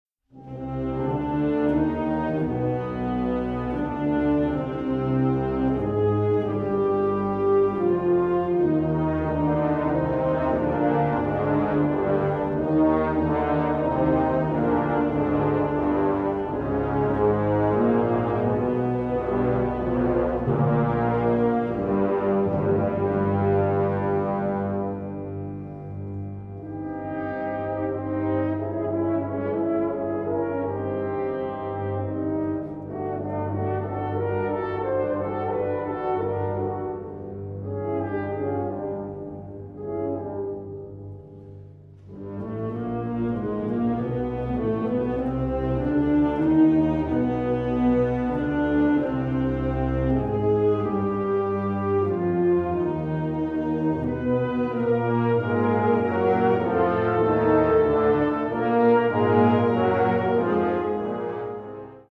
Sinfonisch poem